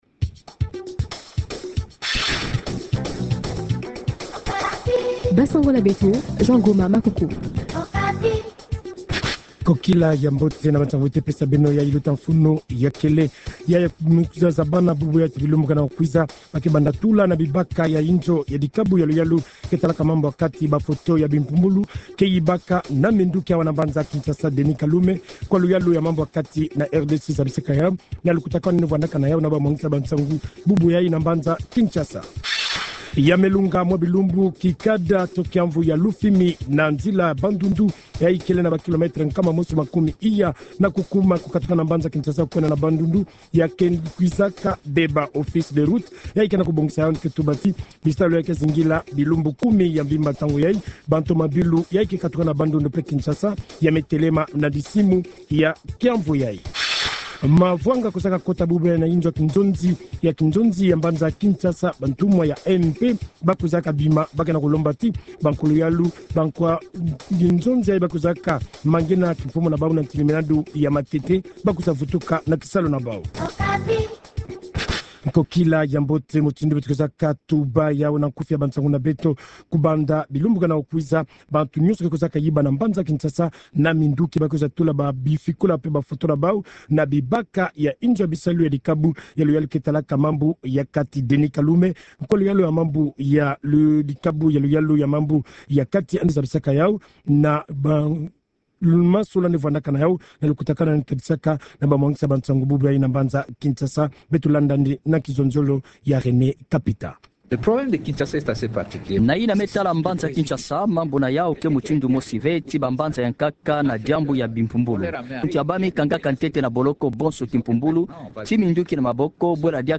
Journal Kikongo